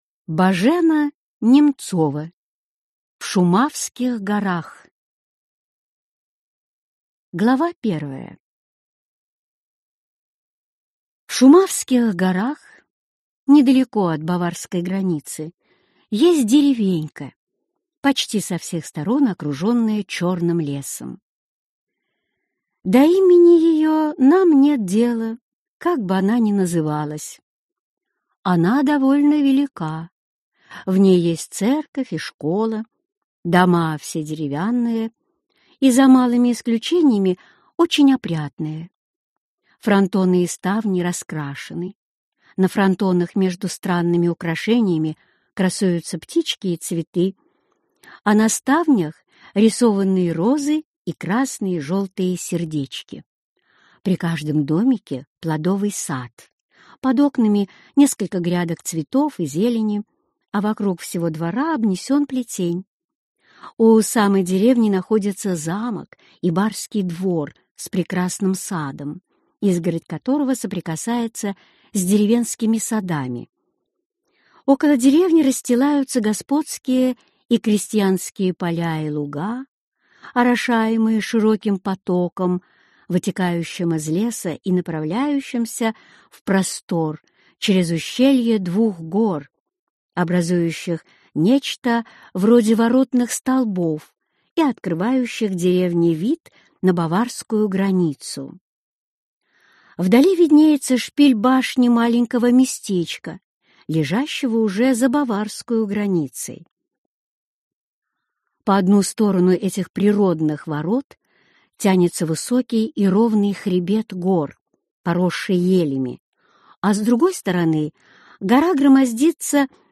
Аудиокнига В Шумавских горах | Библиотека аудиокниг
Прослушать и бесплатно скачать фрагмент аудиокниги